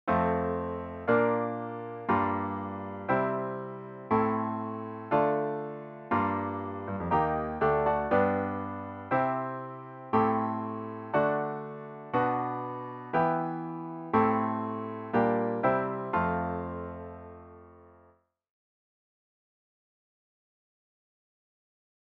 I’ve created a chord progression (see below) that does just that.
There are several methods of changing key: common chord, common tone, etc. In this case, I’ve used what’s best described as an abrupt modulation, by using the Eb chord as a bVII of the original key of F major. That Eb gets reinterpreted as a bVI in the new chorus key of G major.
To return to F major I used a Gm chord with a Bb in the bass.
As you can probably tell, there’s a bit of an energy drop when you return to F major, which comes from the key moving downward.